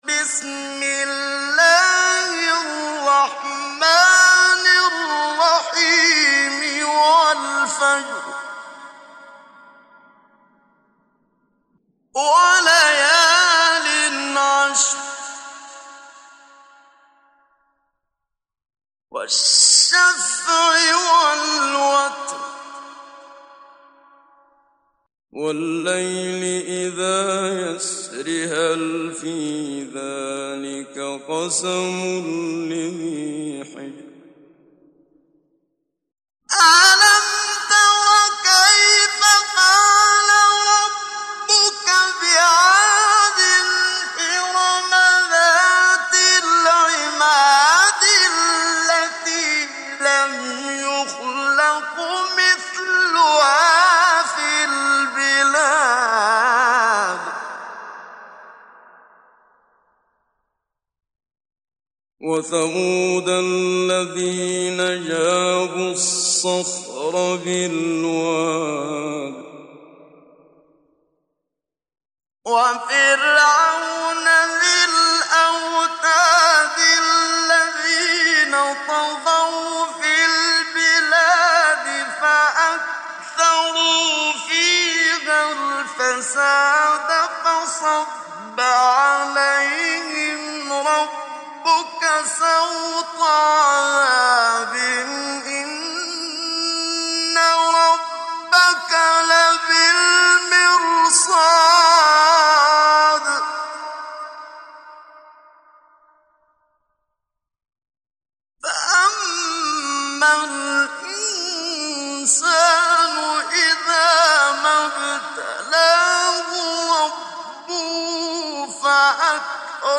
محمد صديق المنشاوي – تجويد